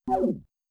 keyboard delete letter.wav